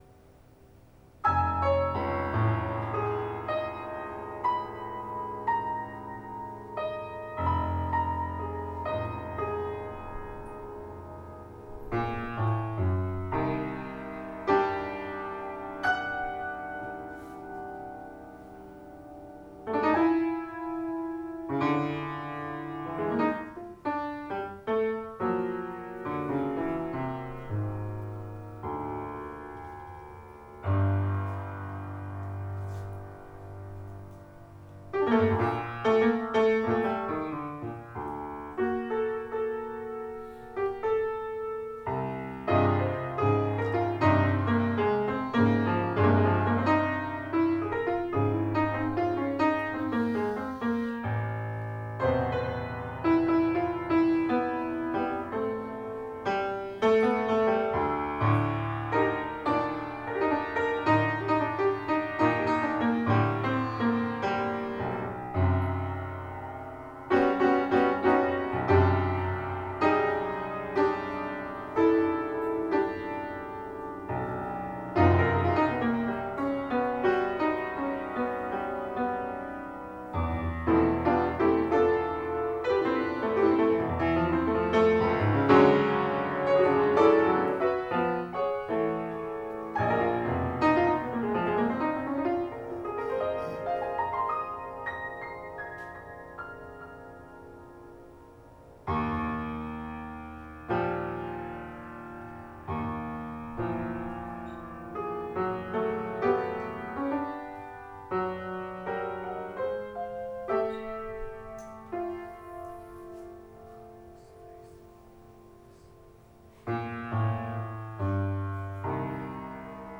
(DE) piano